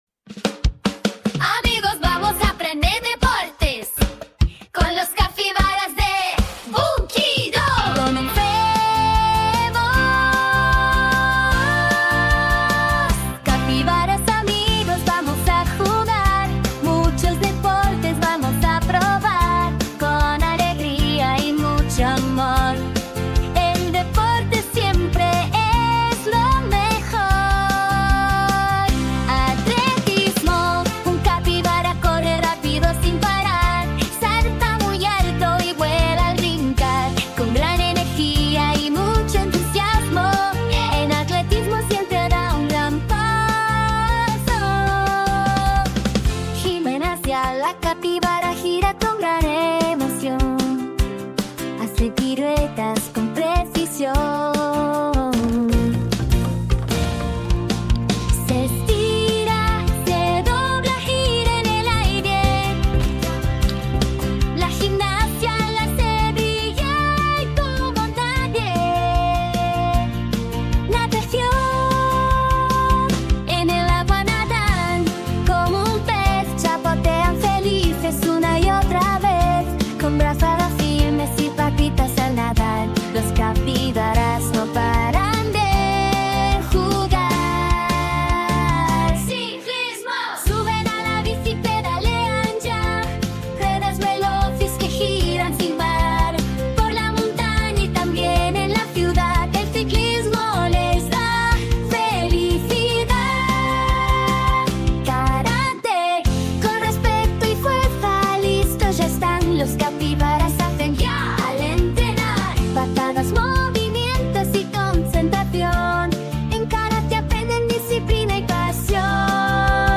🐾⚽🏀🎾 En este divertido video los capibaras nos enseñan diferentes deportes de una forma fácil, tierna y entretenida. Verás cómo juegan fútbol, lanzan la pelota de básquet, practican voleibol y muchos más. Con colores llamativos, música alegre y personajes adorables, los niños podrán aprender y reconocer cada deporte mientras se divierten.